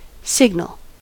signal: Wikimedia Commons US English Pronunciations
En-us-signal.WAV